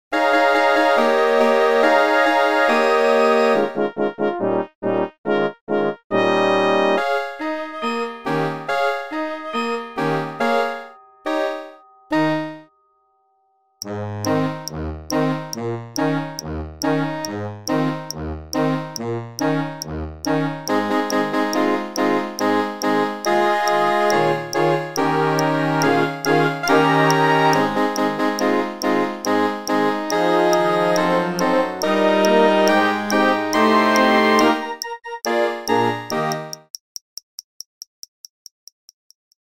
Orkiestrowa , Rozrywkowa